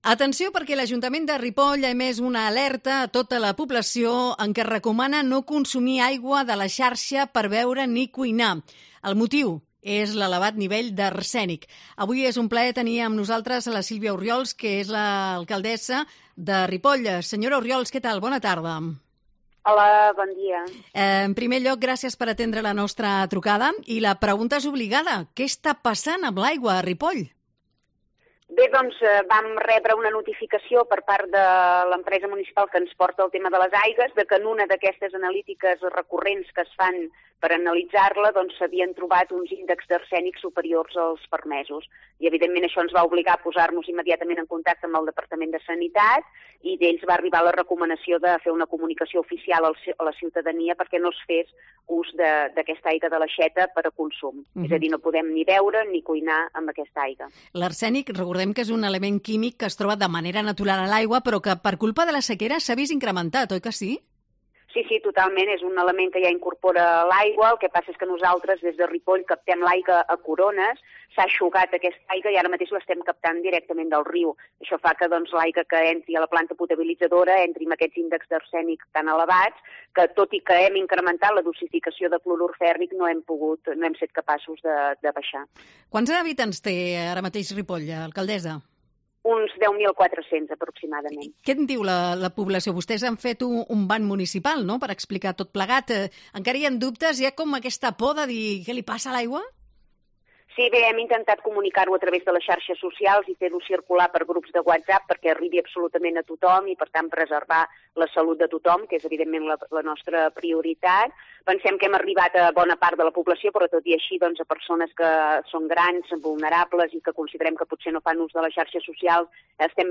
Se recomienda a toda la población que se abstengan de consumir agua de la red para beber o cocinar. La alcaldesa de Ripoll, Silvia Orriols, nos explica la situación.